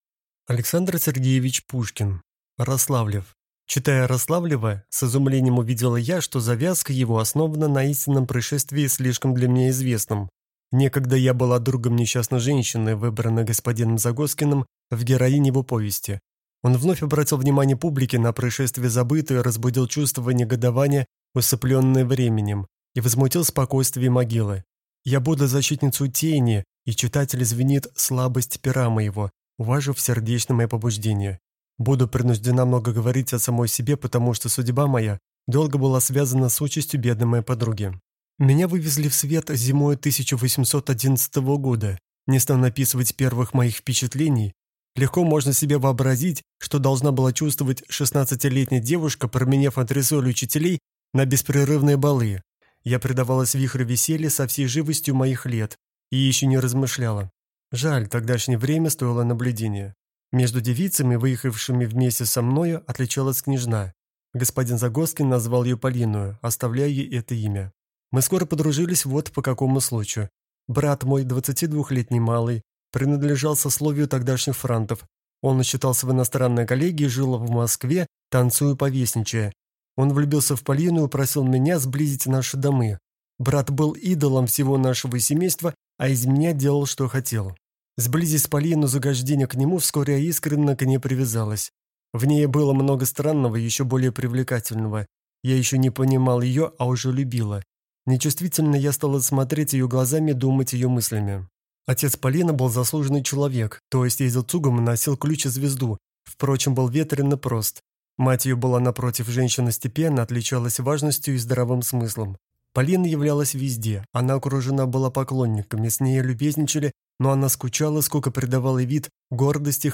Аудиокнига Рославлев | Библиотека аудиокниг